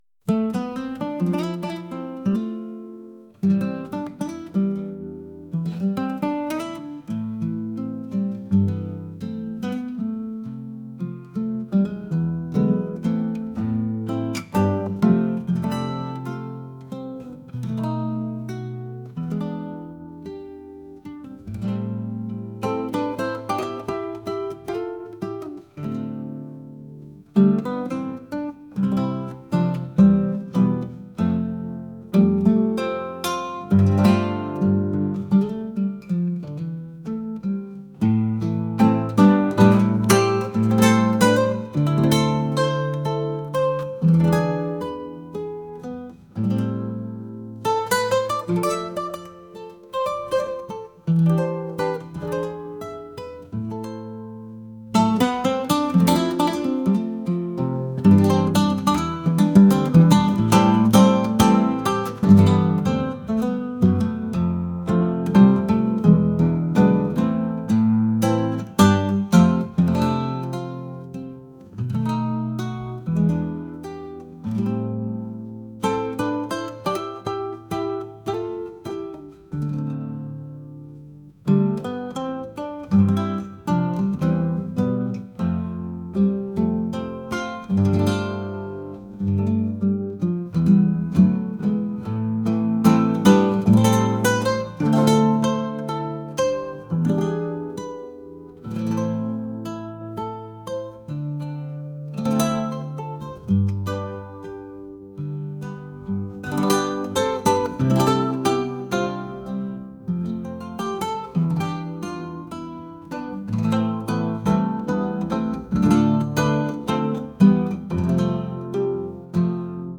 acoustic | soul & rnb | pop